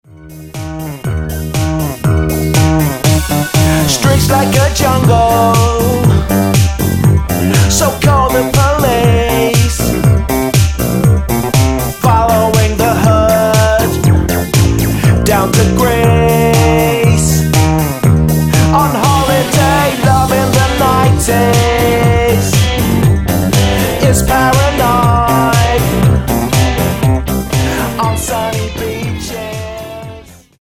--> MP3 Demo abspielen...
Tonart:G Multifile (kein Sofortdownload.